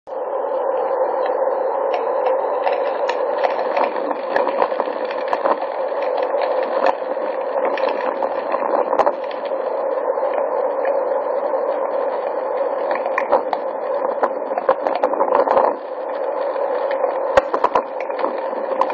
questo che potete ascoltare in questo breve audio , è il vento in corso al passo Miralago... il brusio di fondo è il r umore prodotto dal vento sui boschi di faggio circostanti la stazione...
VENTO-MIRALAGO.mp3